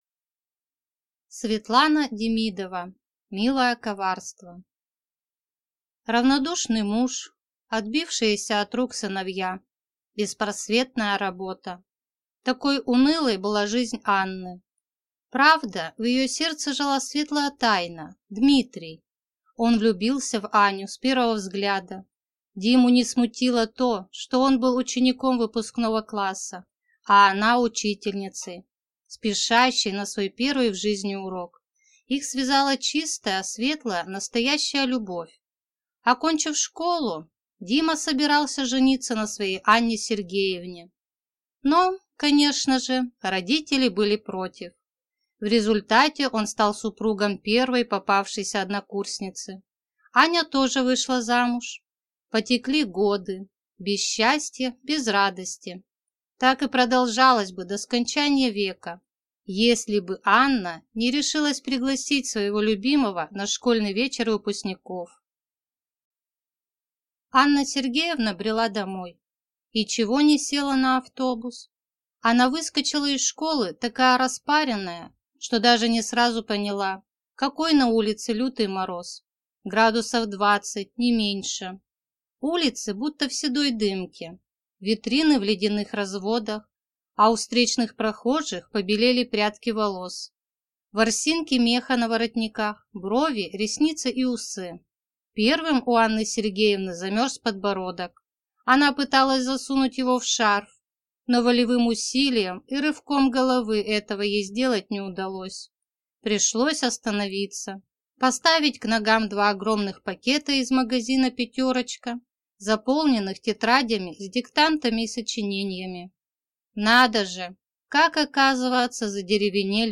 Аудиокнига Милое коварство | Библиотека аудиокниг